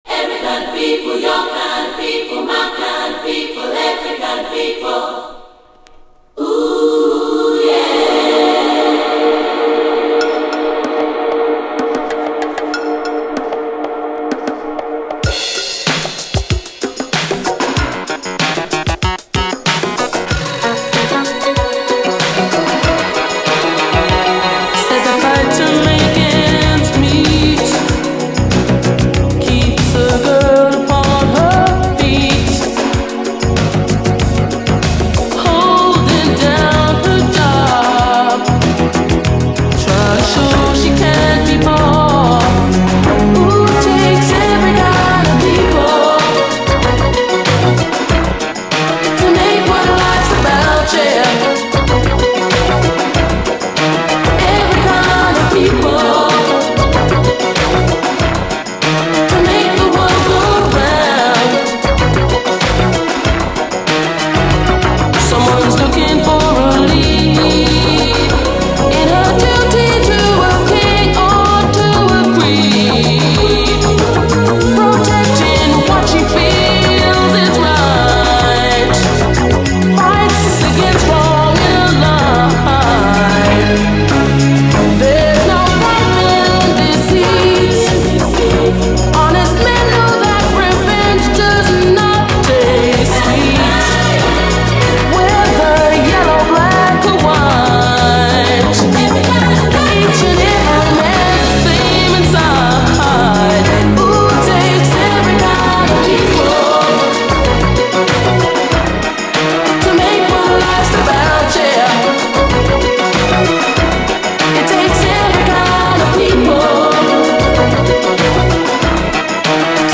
SOUL, 70's～ SOUL, DISCO, 7INCH
バレアリック＆ダビーに広がるシンセ・サウンド＆コーラス・ハーモニーの美しさがヤバい領域に到達しています。